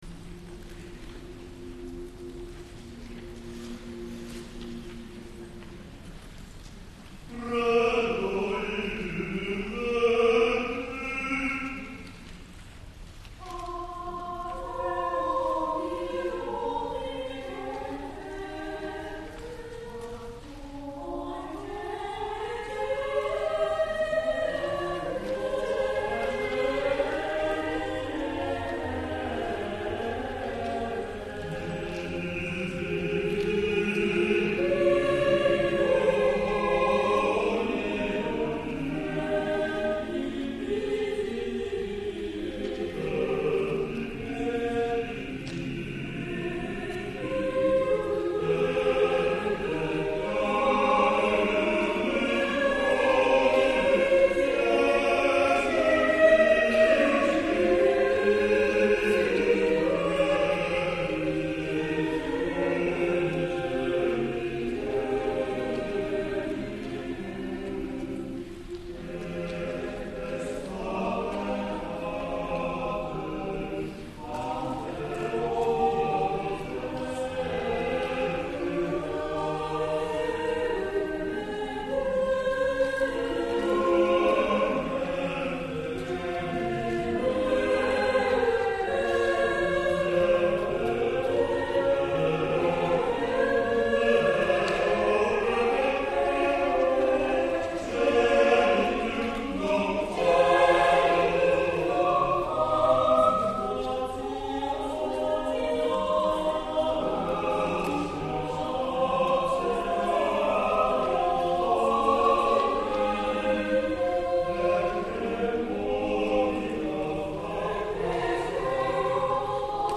第36回野田市合唱祭
野田市文化会館
Mass for four voices より　William Byrd　バード